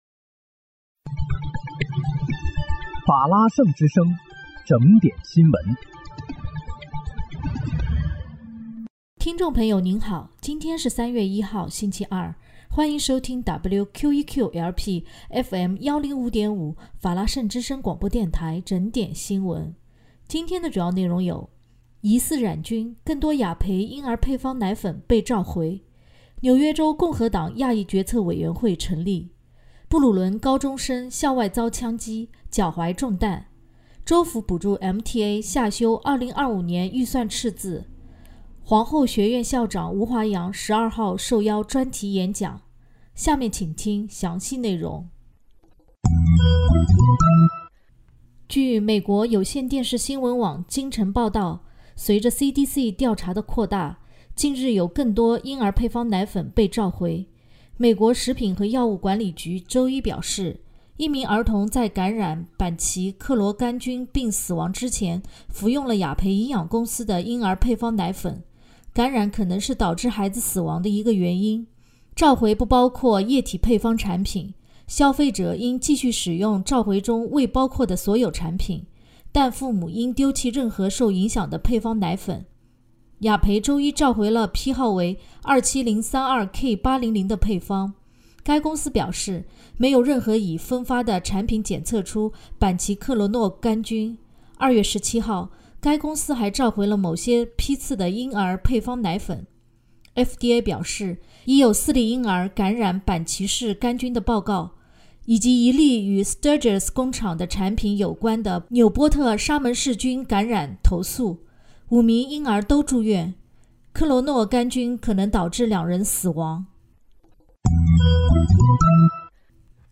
3月1日（星期二）纽约整点新闻